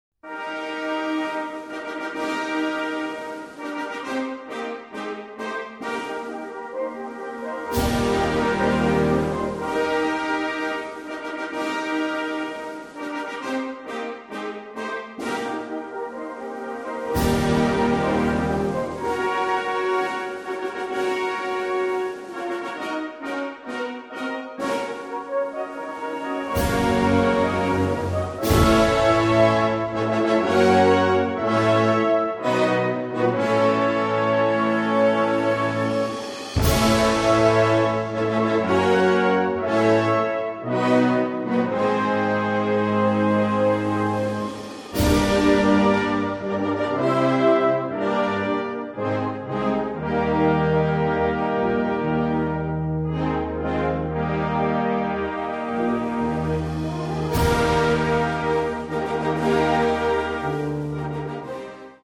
This is very aptly portrayed by the trombones.